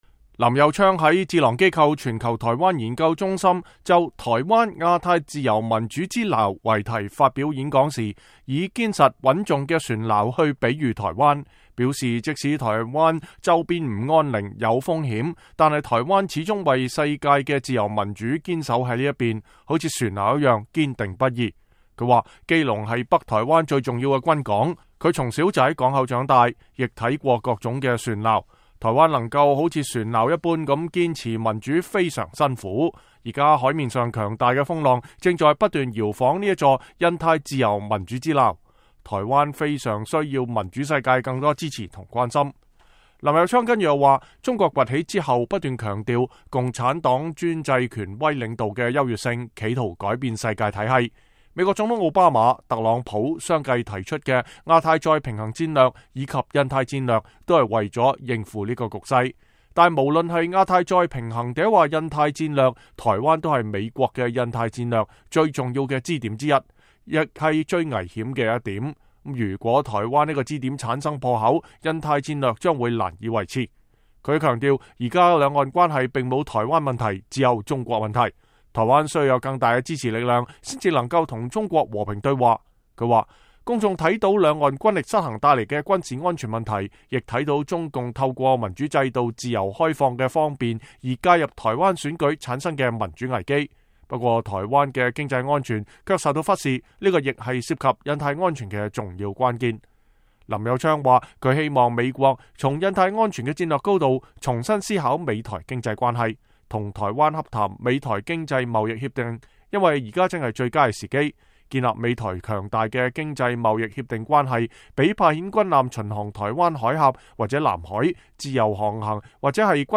正在美國訪問的台灣基隆市長林右昌，星期三在華盛頓一場演講中公開呼籲美國加強對台灣民主、經濟和安全的支持，不要讓台灣成為印太地區自由民主的突破口。